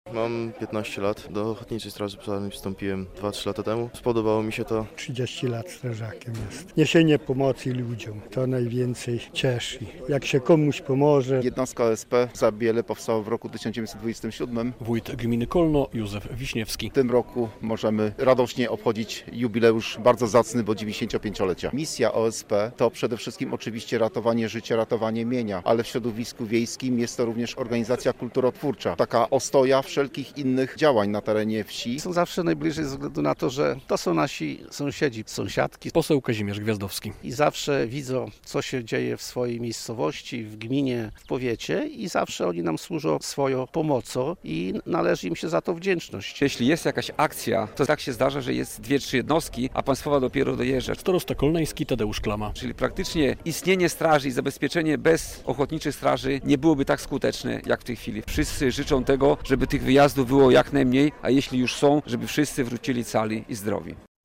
Jednostka OSP w Zabielu koło Kolna obchodzi 95-lecie istnienia - relacja